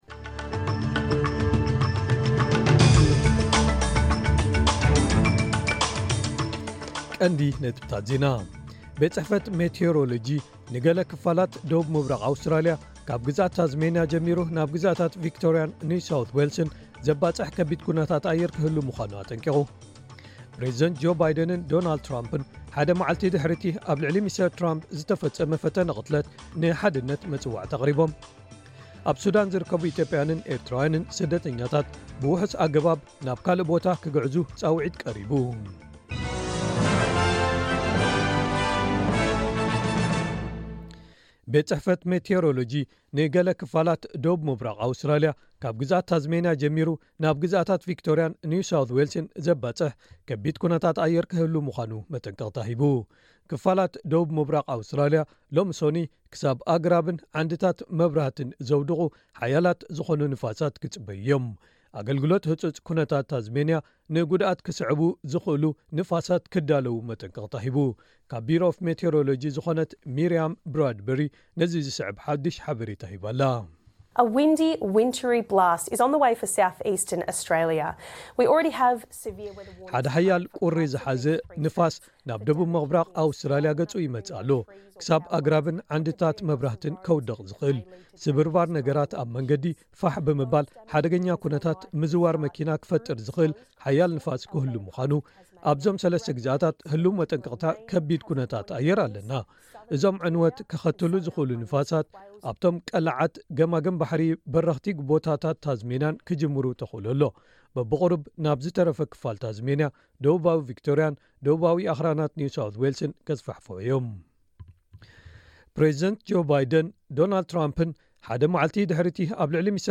ዕለታዊ ዜና ኤስ ቢ ኤስ ትግርኛ (15 ሓምለ 2024)